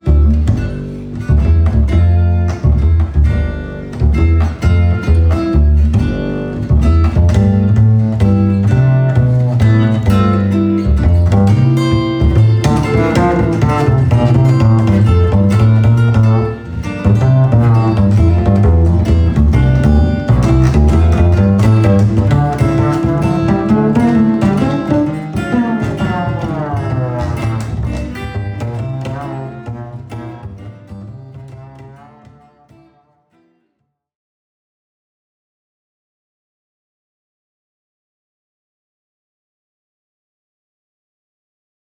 Kontrabass Bühne